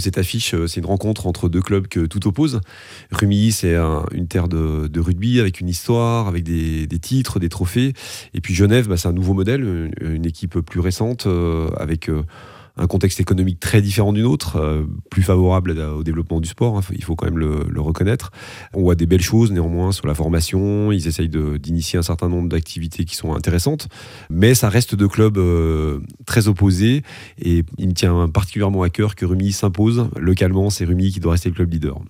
le club de rugby de Rumilly